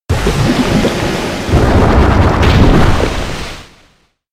Sludge Wave
sludge-wave.mp3